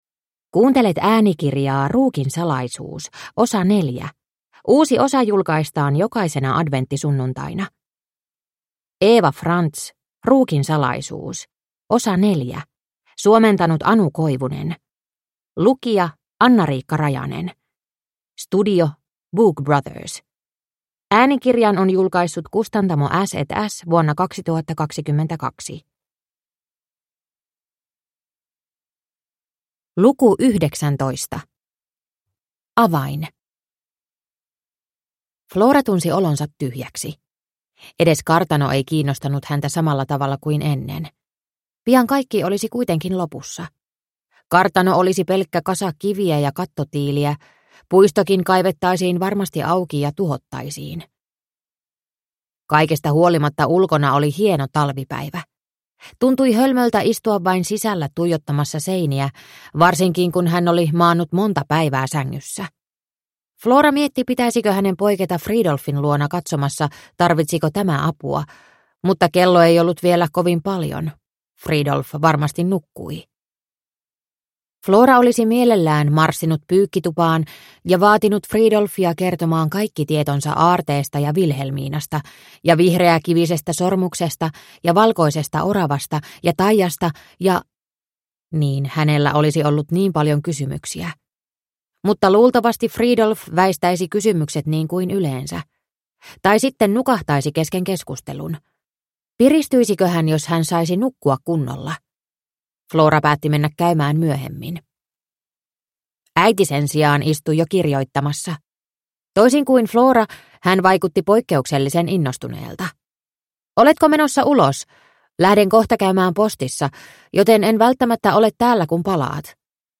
Ruukin salaisuus osa 4 – Ljudbok – Laddas ner
Entä mitä tapahtui sen omistaneelle von Hiemsin perheelle?Kihelmöivän jännittävä äänikirja on jaettu neljään osaan, jotka julkaistaan viikon välein jolukuussa.